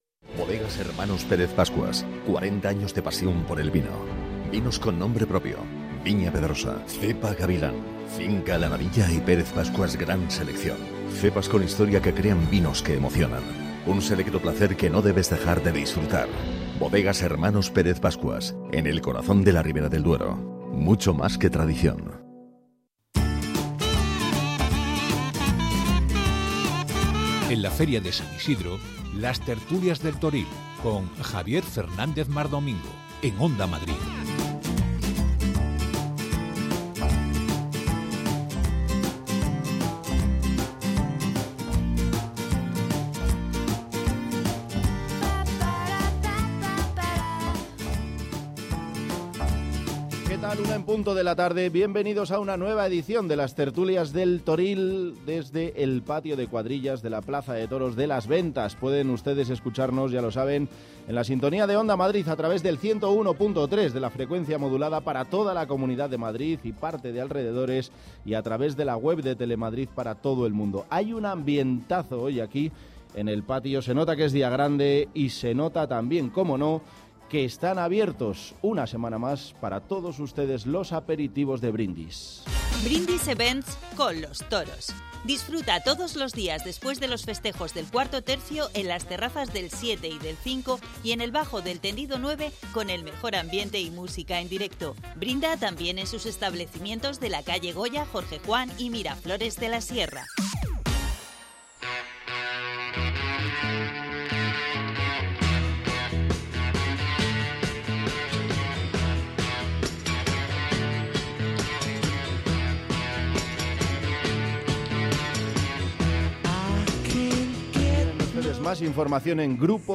Cada día desde la plaza de toros de las ventas Las Tertulias del Toril en directo para contar y analizar lo que haya pasado en la última tarde en la feria de San Isidro y para analizar lo que vaya va a pasar ese mismo día